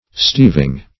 Steeving \Steev"ing\, n.